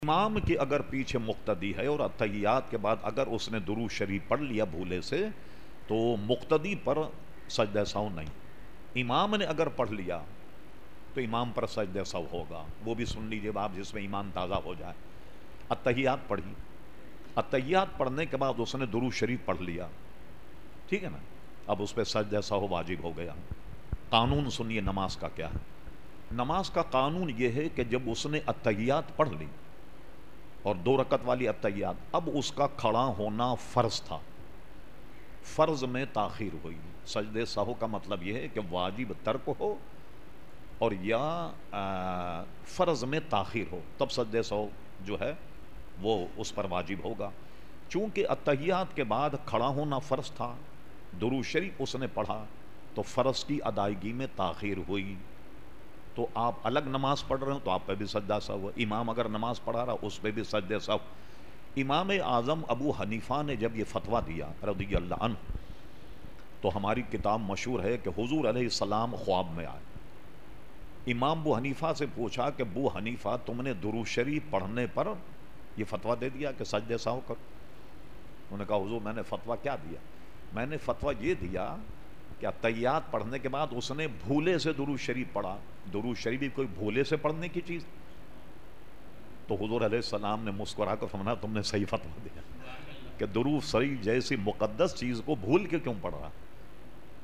Q/A Program held on Sunday 15 August 2010 at Masjid Habib Karachi.
Hazrat Allama Maulana Syed Shah Turab ul Haq Qadri (Q&A)